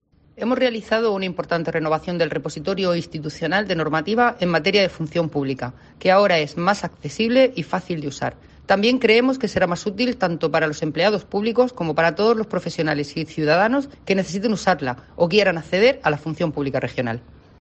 Carmen María Zamora, directora general de Función Pública